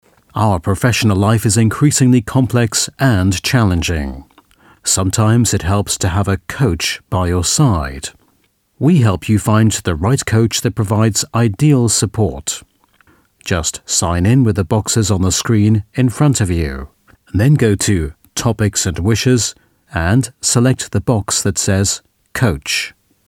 Native Speaker
Englisch (UK)
Explainer Videos